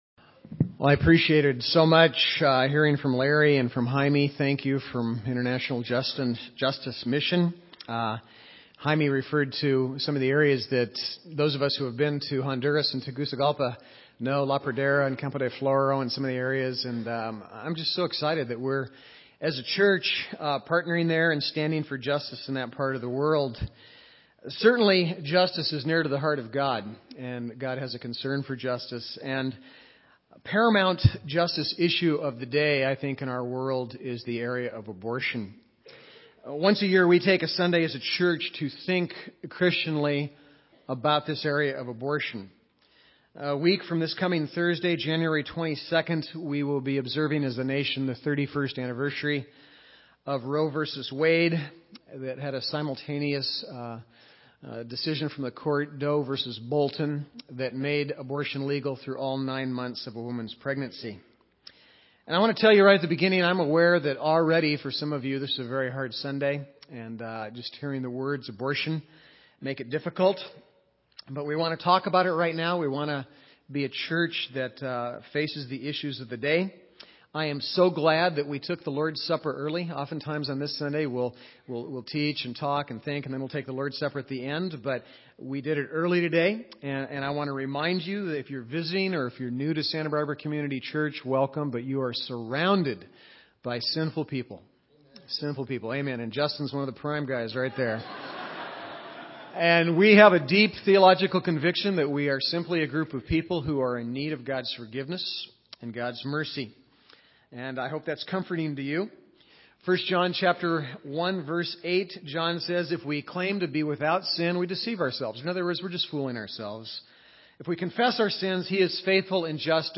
Pro-Life Sunday Service Type: Sunday Preacher